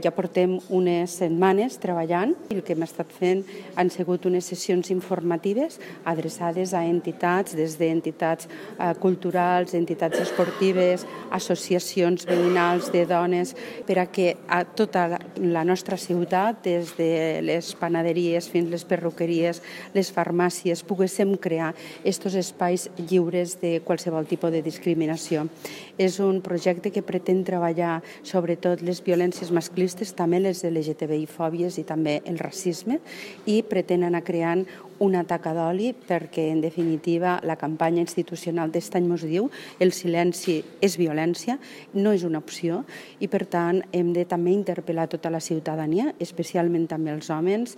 La regidora d’igualtat i feminismes, Mar LLeixà ha assegurat que és imprescindible treballar de forma conjunta entre institucions i agents socials per erradicar les violències masclistes.  Segons Lleixà, l’Ajuntament seguirà treballant per sumar més adhesions a la xarxa comunitària…